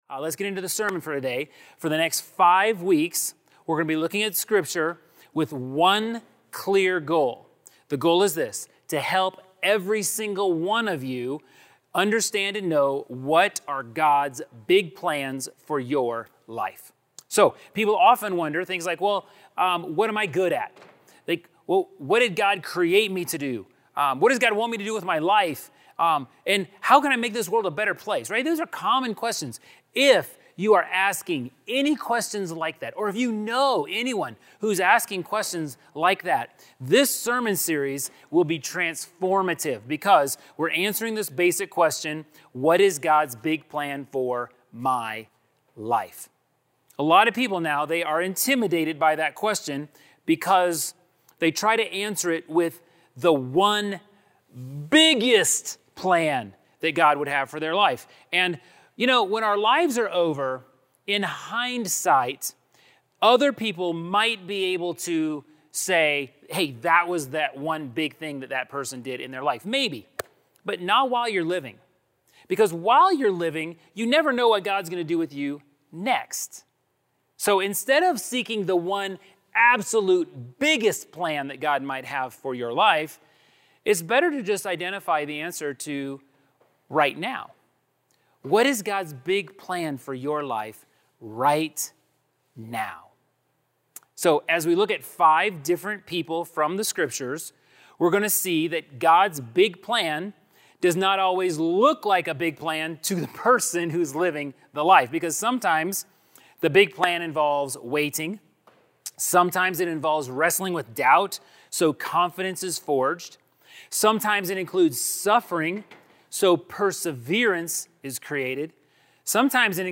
Sermon MP3 Download G2Q Word Doc Download G2Q PDF